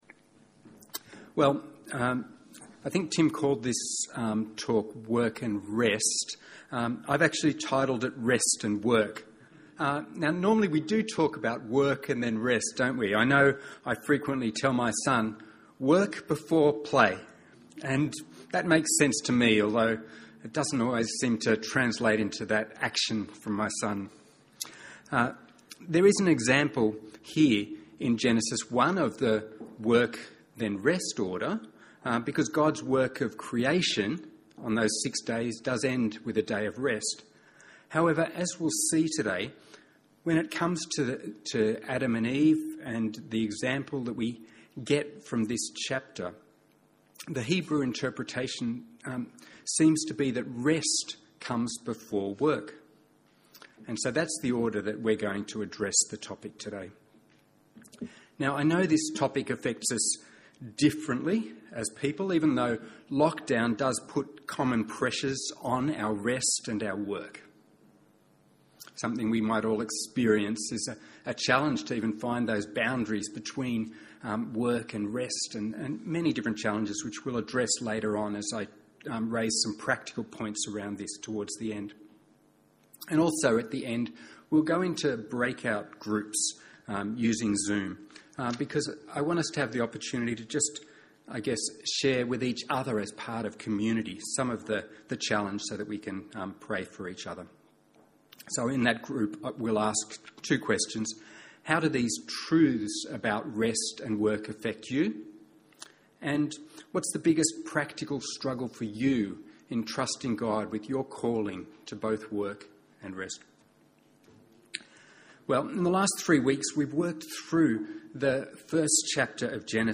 by admin | Sep 26, 2021 | Genesis 1-3, Sermons 2021